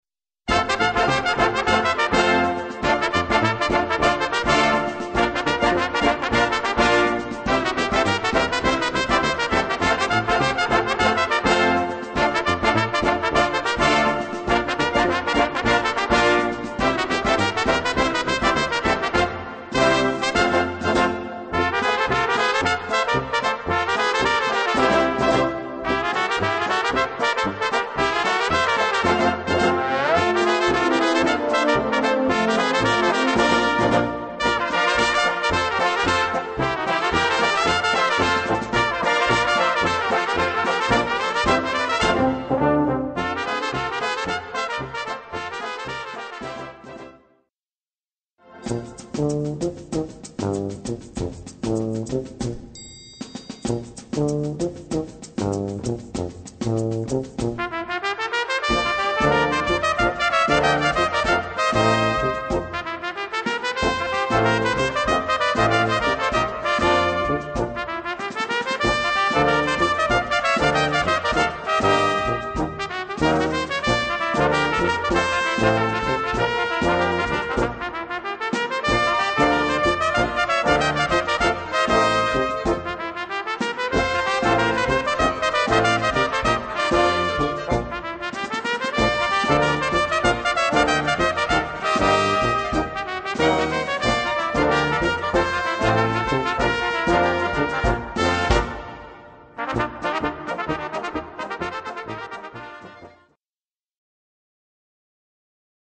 Gattung: Swing-Polka für 2 Solo-Trompeten und Blasorchester
Besetzung: Blasorchester